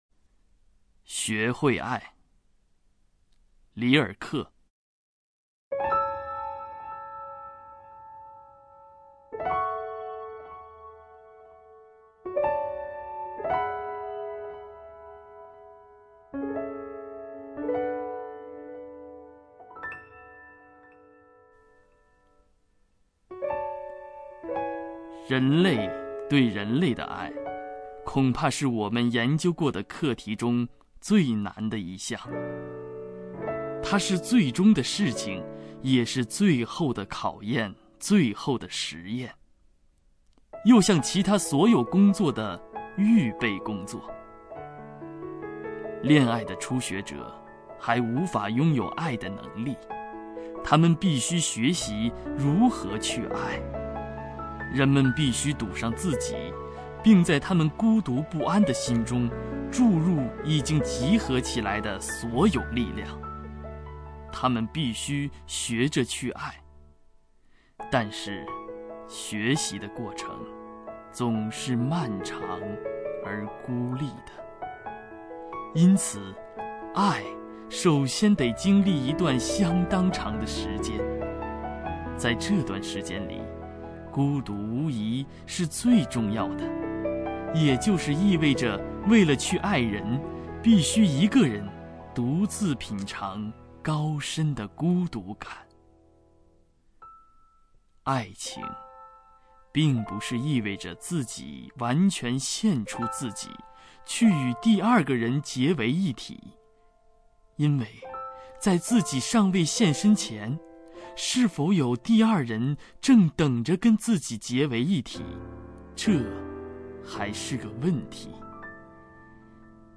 名家朗诵欣赏